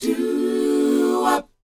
DOWOP E 4E.wav